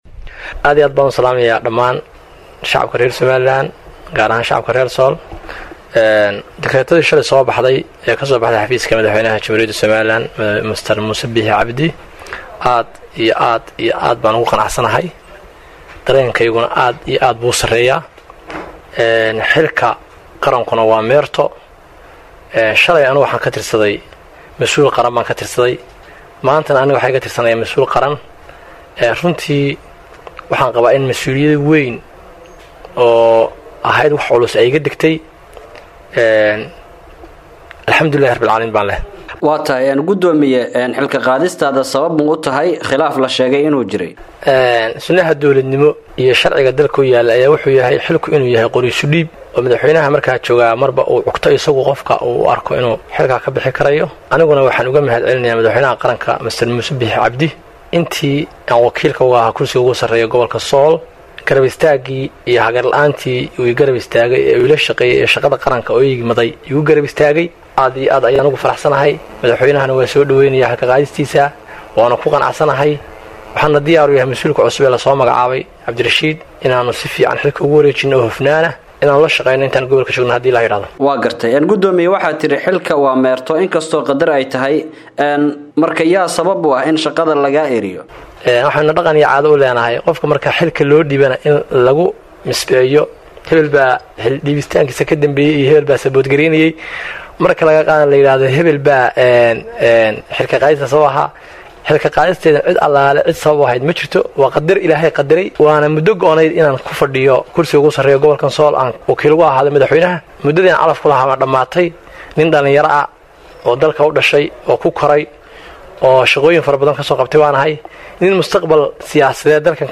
Wareysi-Gudoomiyihii-Gobolka-Sool-.mp3